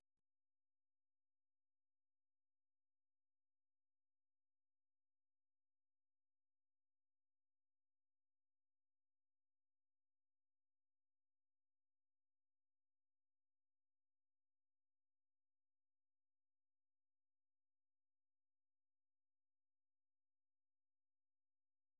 Название моста широко известно во Франции благодаря народной песне XVI—XVII веков, которая так и называется Sur le pont d’Avignon (с фр.